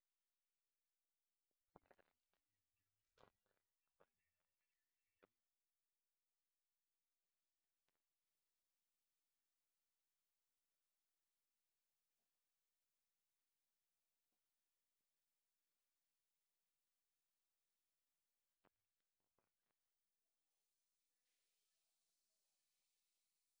Paine - three knocks on wall.wav